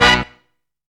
TOP HIT.wav